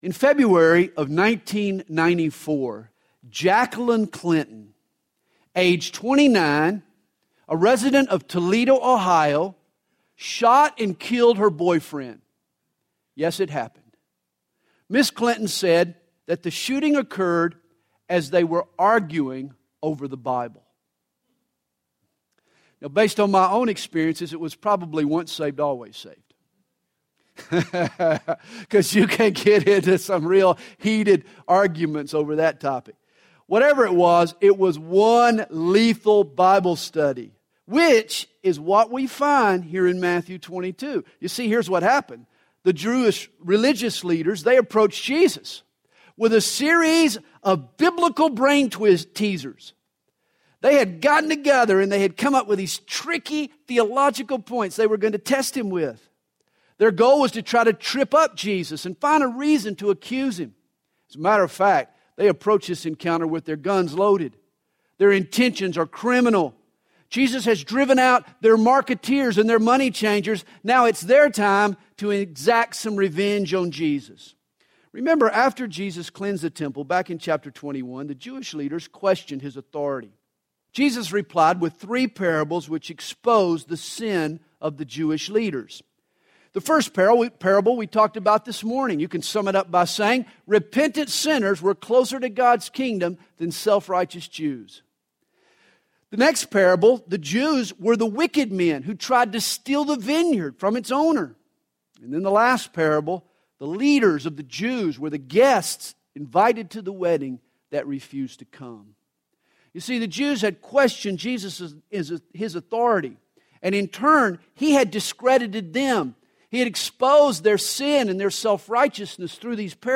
Sermon navigation